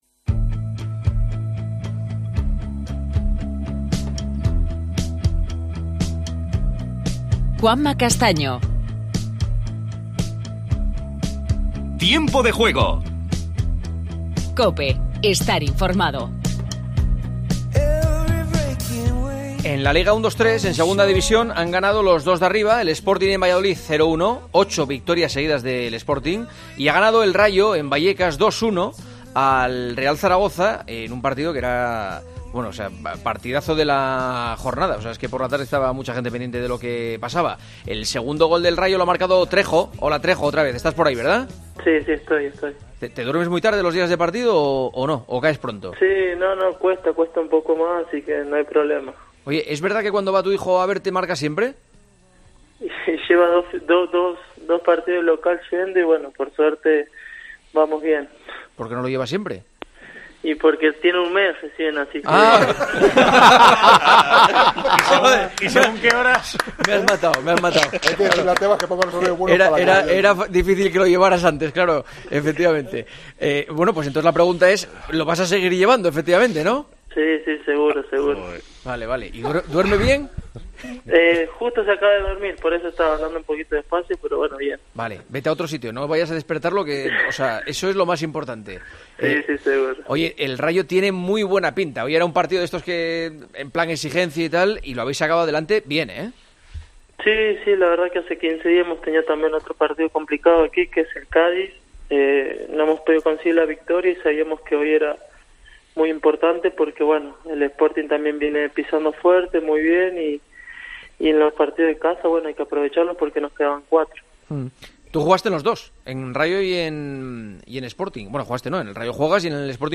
Entrevista con Trejo, jugador del Rayo Vallecano.